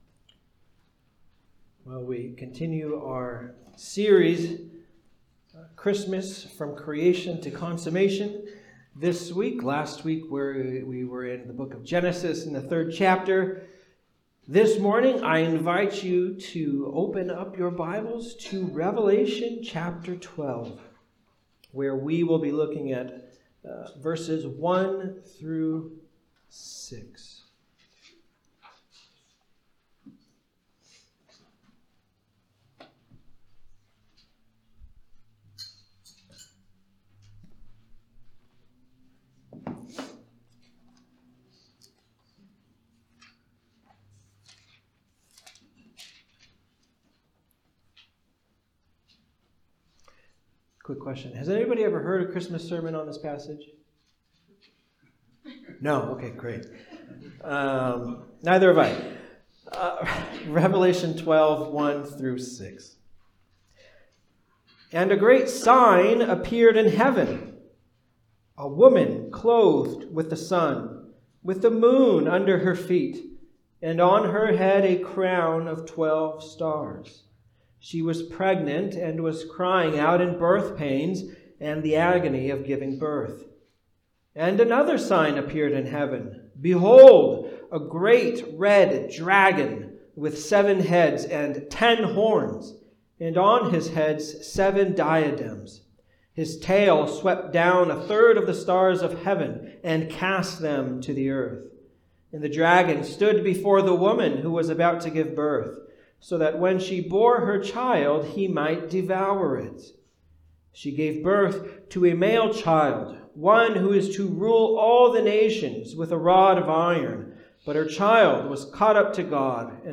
Passage: Revelation 12:1-6 Service Type: Sunday Service